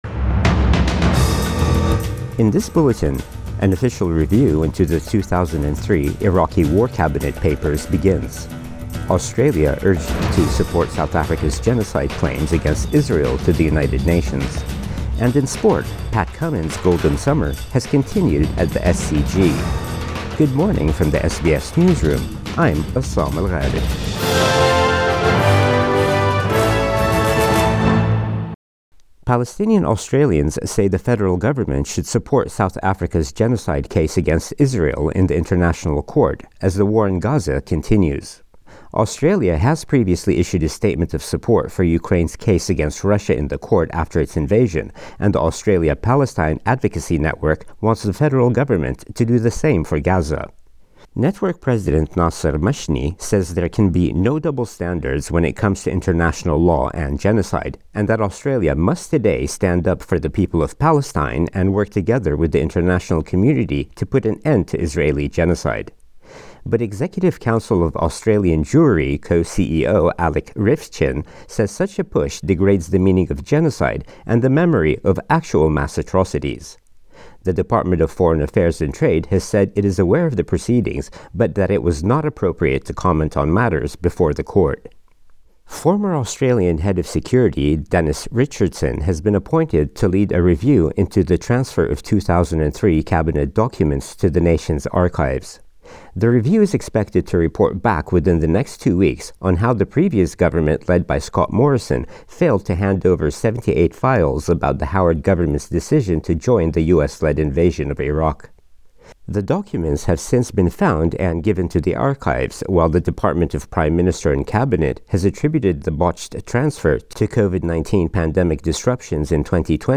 Morning News Bulletin 4 January 2024